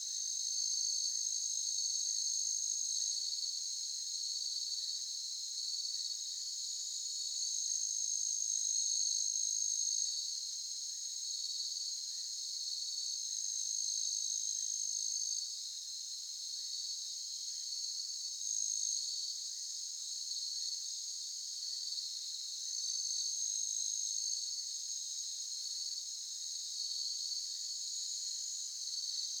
jse_cricket-ambience-evening-jungle-distant-bird-vietnam.ogg